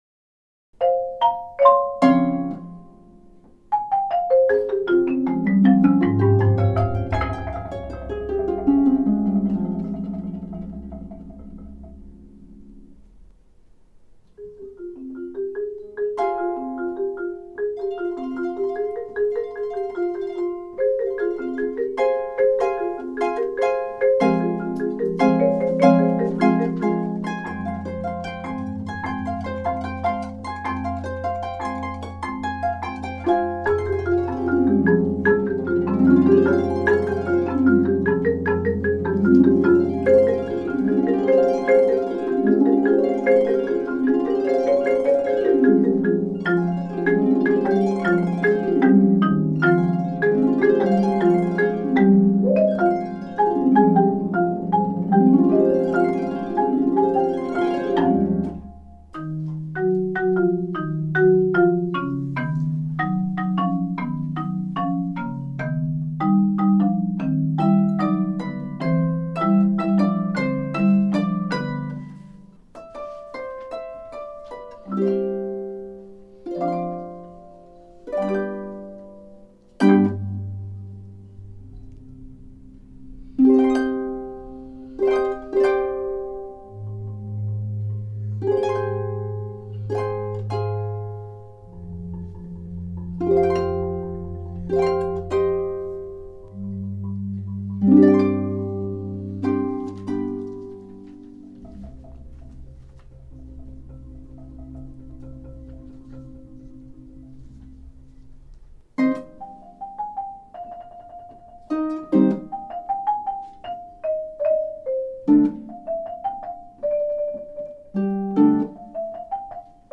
w/o keys   (2006) Juilliard Fall 2006, for Harp and Marimba.